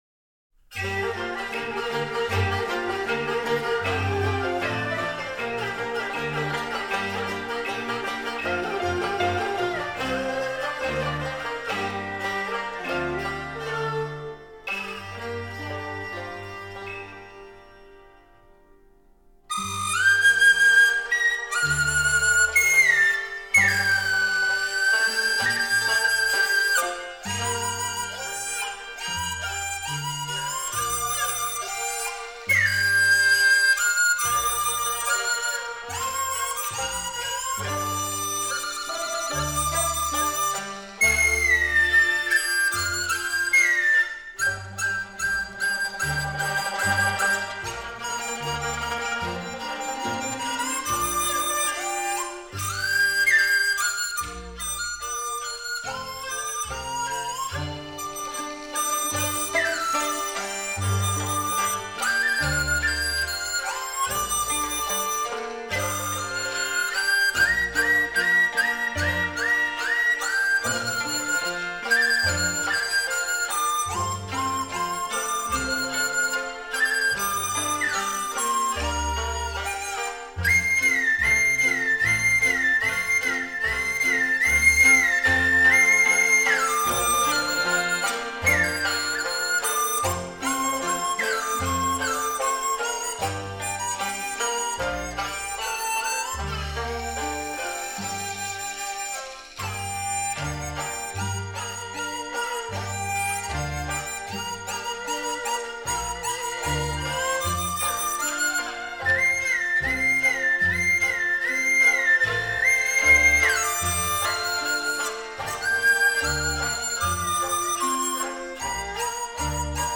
中国吹管乐
梆笛
曲调明快流畅